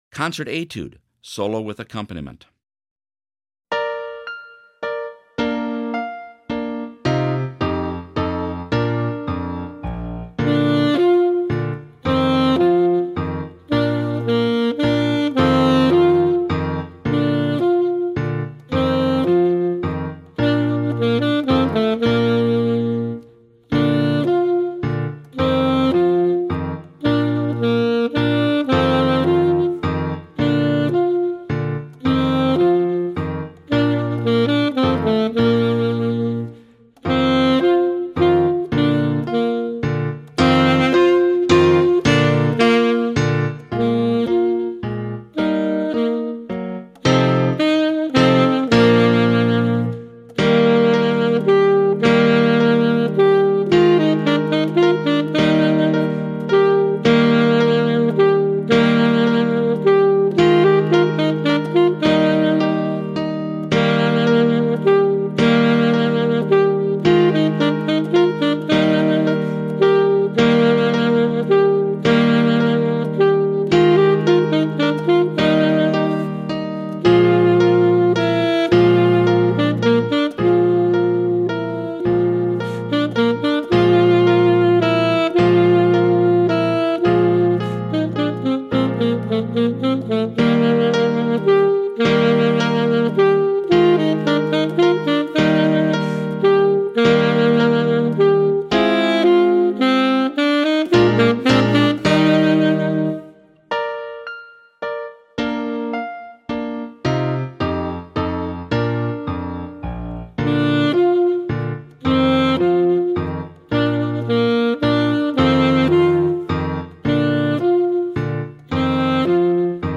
Solo with Piano Accompaniment – Performance Tempo
Concert-Etude-Solo-and-Accomp.mp3